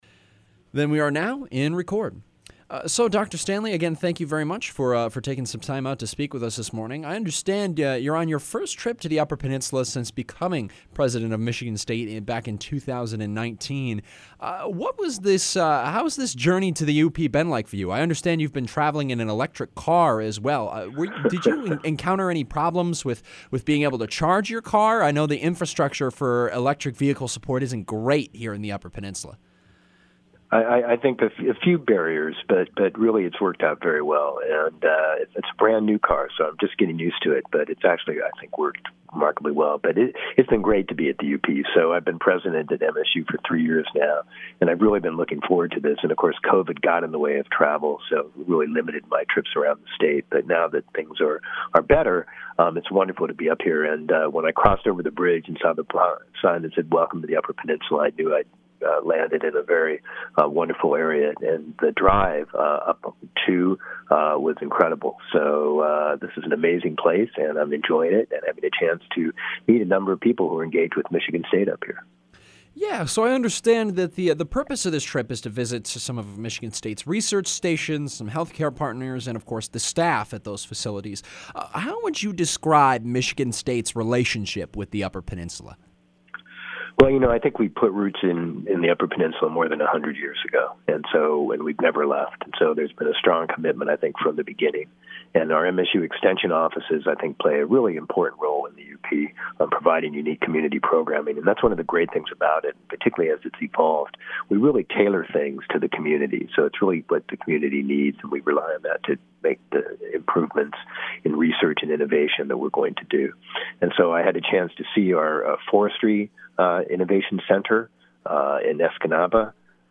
Michigan State University President Sam Stanley visited the Upper Peninsula on Tuesday this week to tour the university’s research stations and meet with staff. In an interview with RRN News, Stanley also spoke about the work of the MSU Extension Service in the U.P., and how the role of extension has changed in recent years.